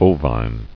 [o·vine]